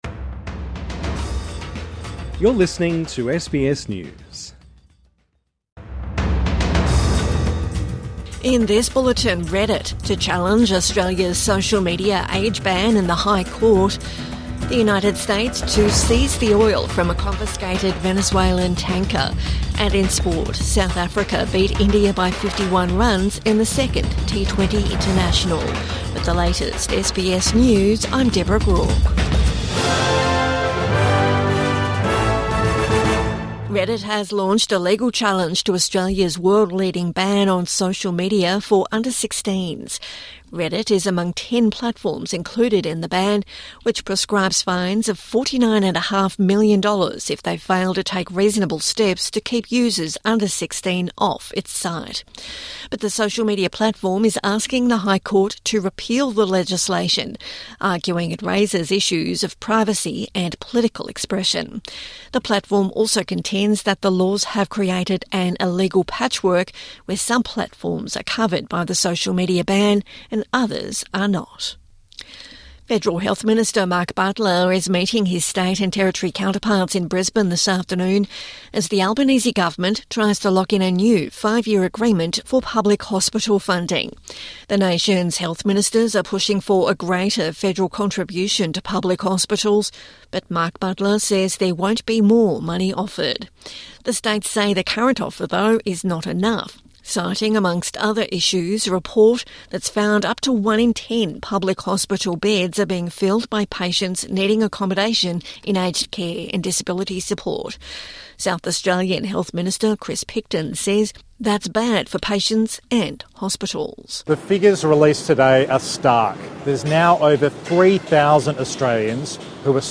Reddit files legal challenge to teen social media ban | Midday News Bulletin 12 December 2025